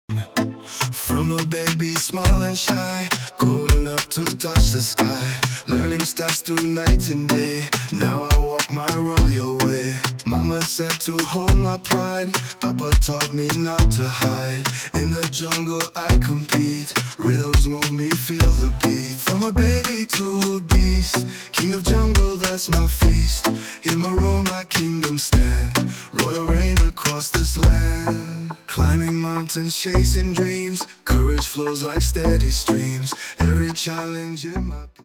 Short version of the song, full version after purchase.
An incredible Afrobeats song, creative and inspiring.